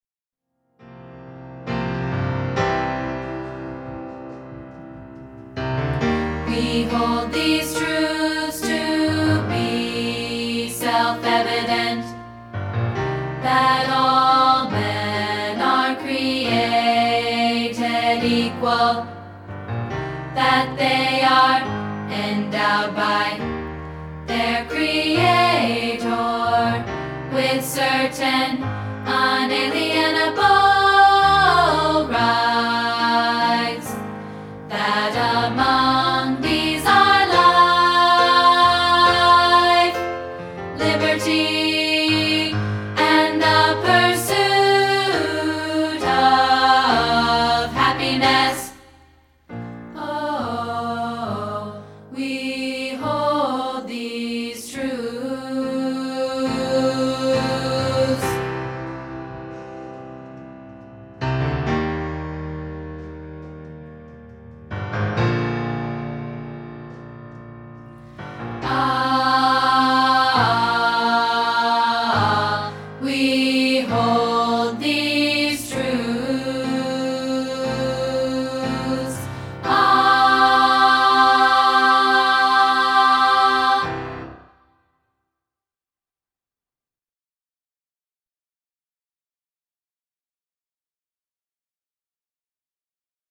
rehearsal track